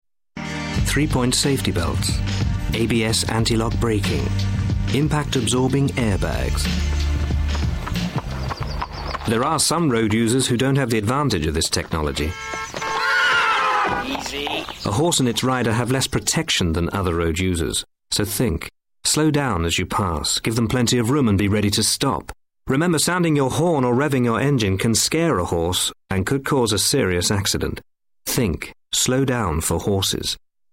LISTEN: Listen to the THINK! radio advert